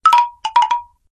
chimes.ogg